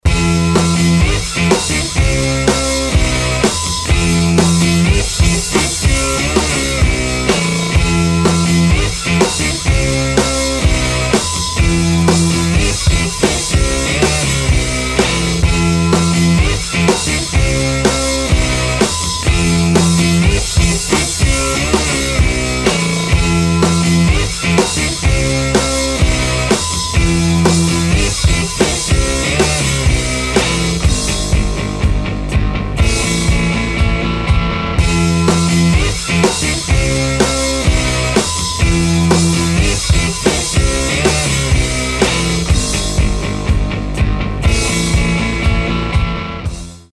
• Качество: 320, Stereo
гитара
громкие
без слов
ударные
Крутой рок рингтон